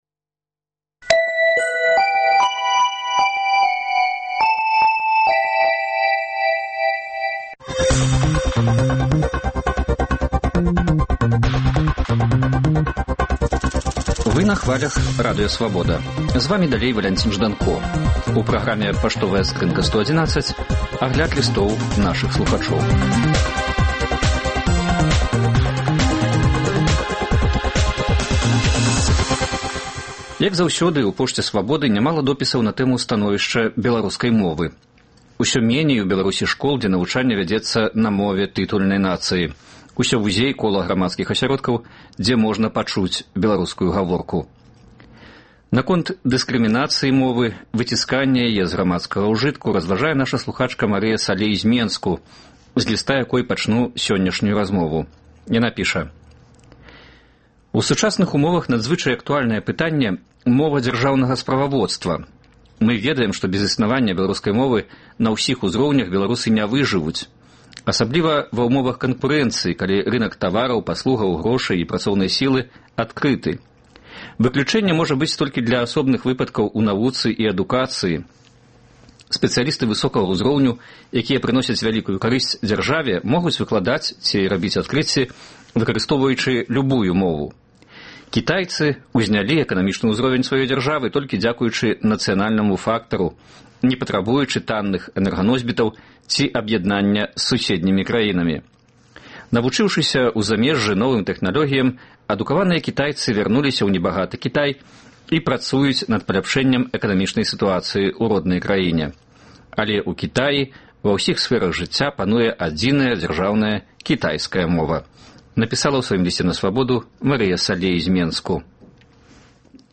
Гэты ды іншыя лісты слухачоў Свабоды чытае і камэнтуе